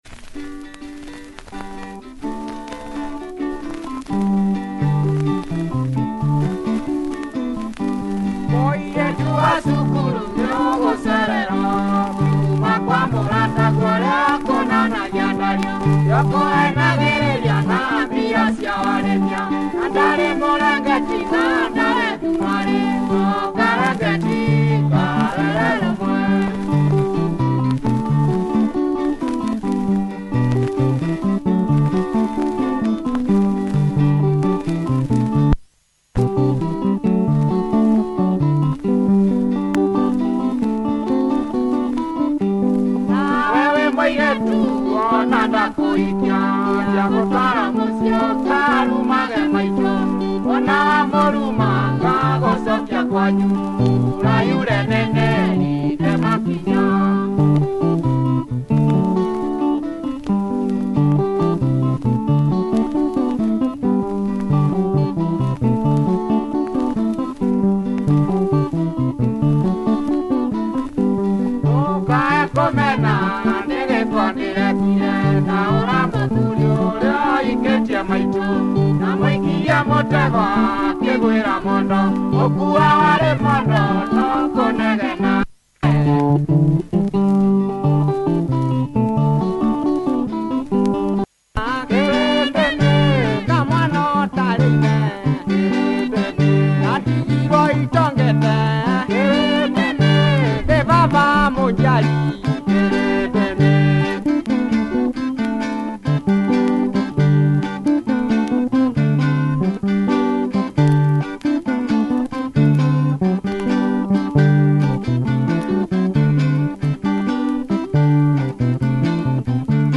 Traditional Kikuyu guitar effort